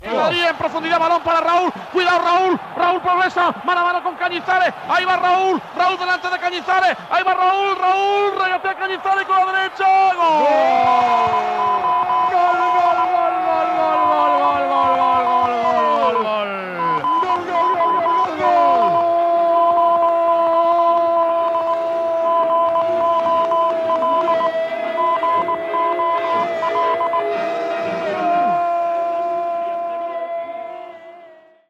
Transmissió de la final de la Copa d'Europa de futbol masculí, des de l'estadi de Saint Denis (París), entre el Real Madrid i el València. Narració del gol de Raúl del Real Madrid.
Esportiu